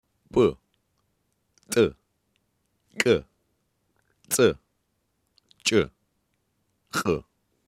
Грузин тіліндегі абруптивтік дыбыстар